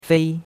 fei1.mp3